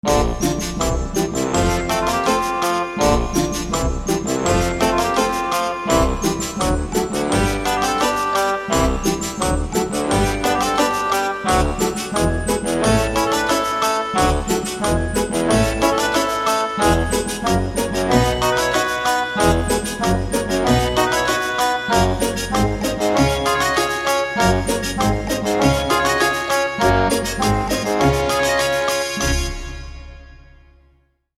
instrumental music cues can also be used to